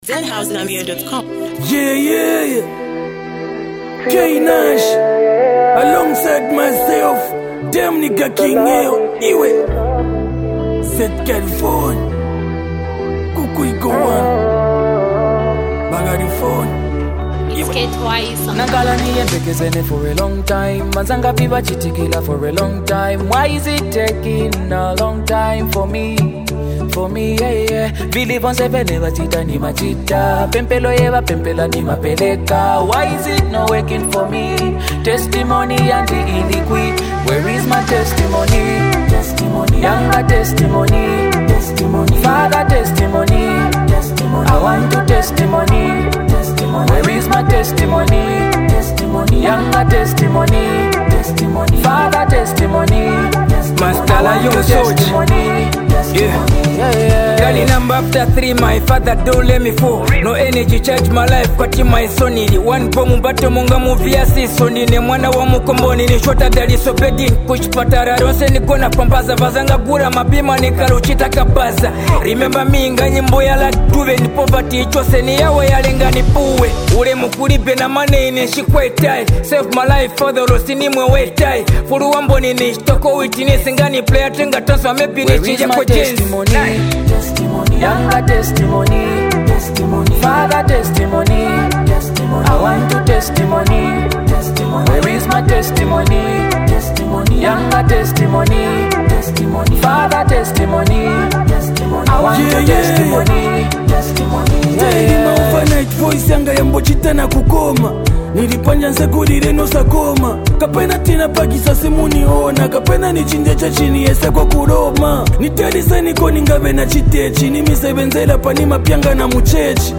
is a powerful anthem of gratitude and victory
soulful production